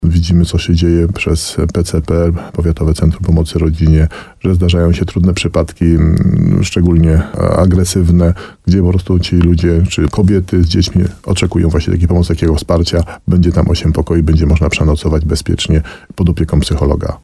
– Takie wsparcie jest bardzo potrzebne – mówił w programie Słowo za słowo na antenie RDN Nowy Sącz starosta nowosądecki Tadeusz Zaremba.